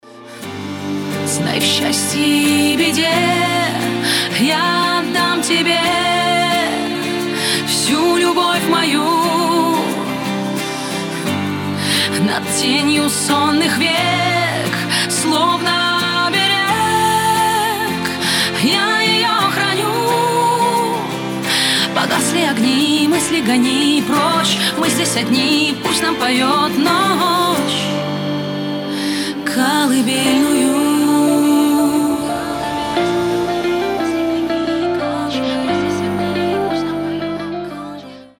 • Качество: 320, Stereo
мелодичные
Alternative Rock
красивый женский голос
русский рок
сильный голос